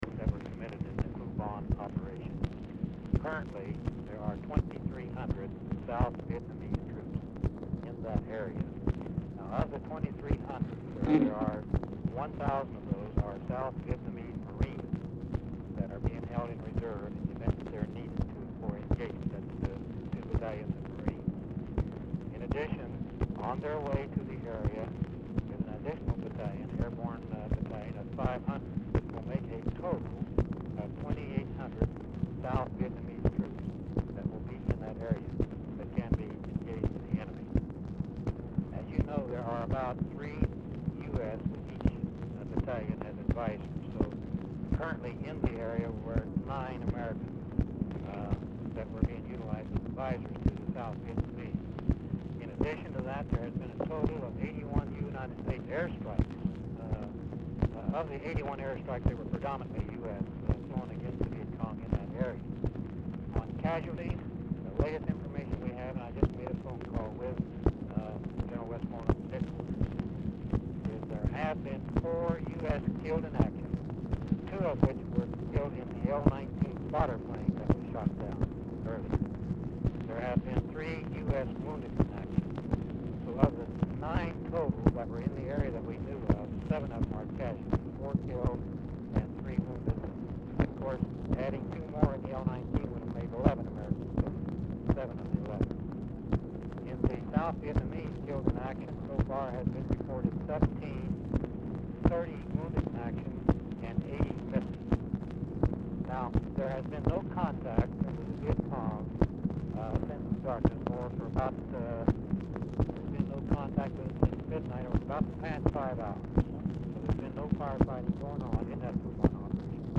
Telephone conversation # 8214, sound recording, LBJ and PENTAGON COMMAND CENTER, 6/30/1965, 4:51PM
RECORDING STARTS AFTER CONVERSATION HAS BEGUN
Format Dictation belt